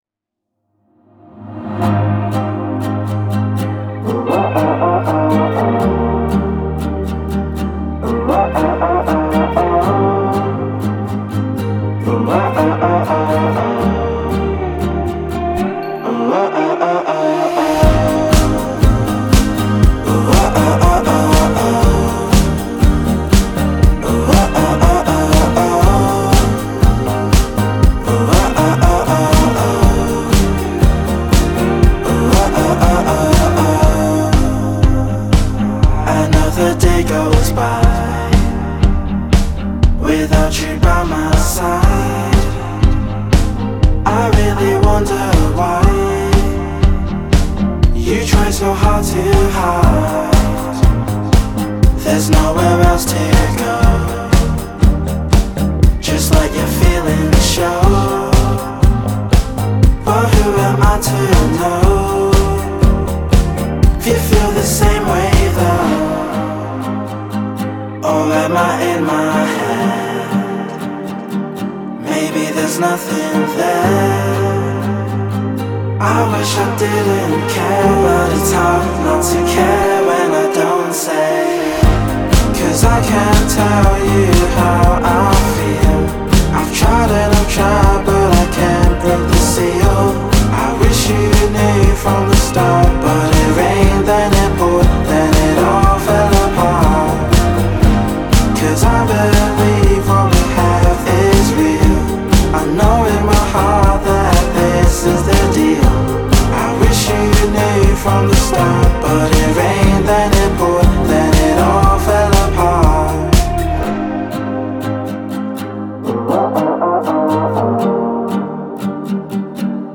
my main genre overall I’d say is pop